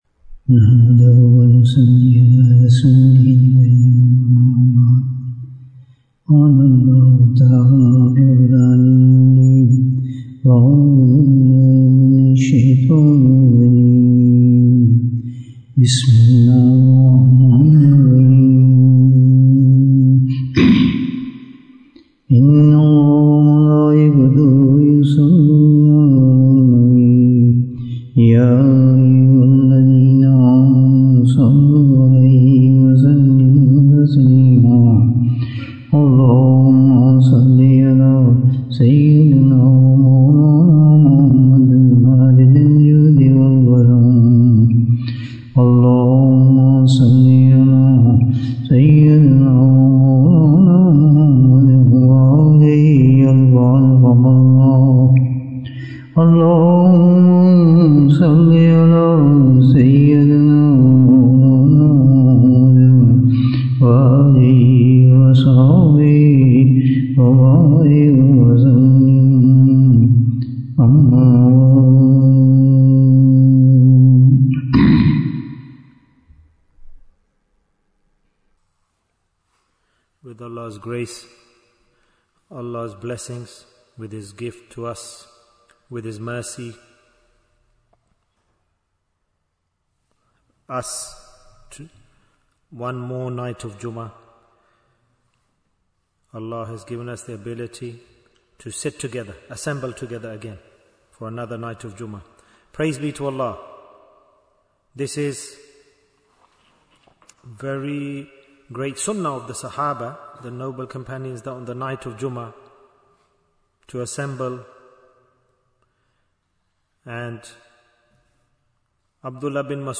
Why Do We Do Ijtema? Bayan, 58 minutes18th December, 2025